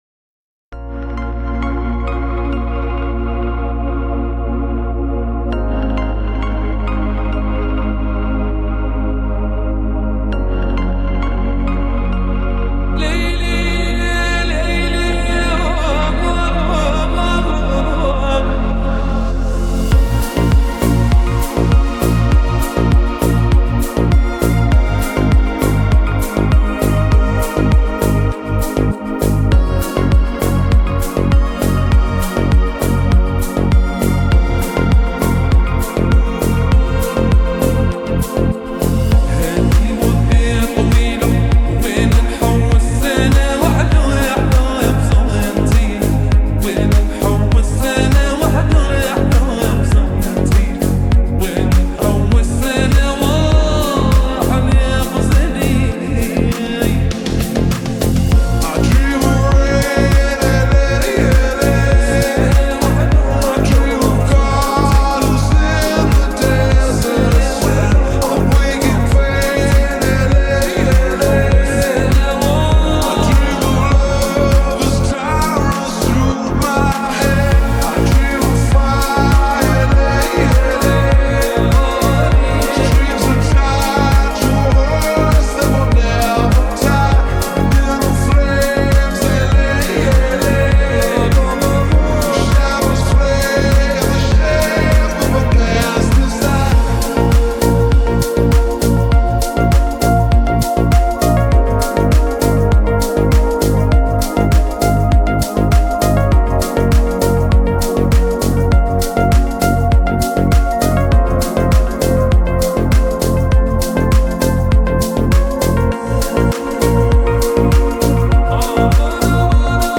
Категория: Deep House музыка
дип хаус Размер файла